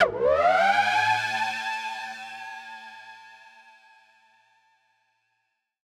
Index of /musicradar/future-rave-samples/Siren-Horn Type Hits/Ramp Up
FR_SirHornD[up]-A.wav